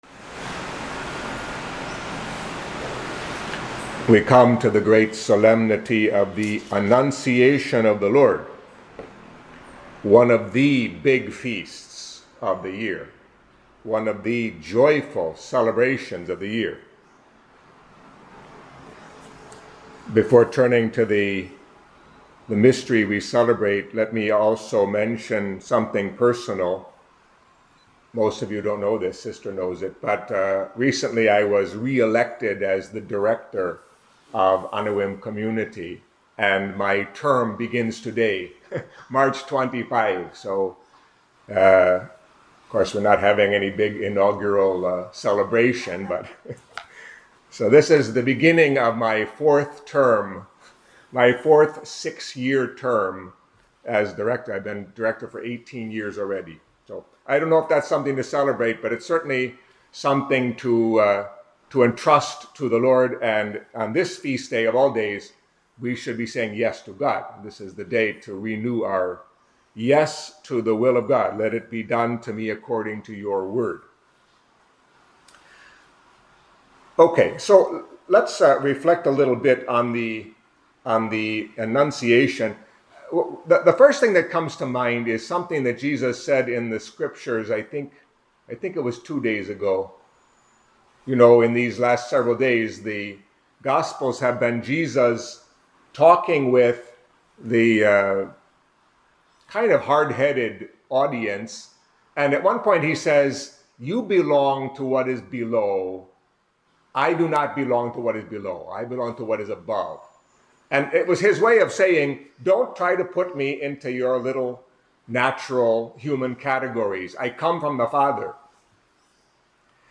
Catholic Mass homily for the Annunciation of the Lord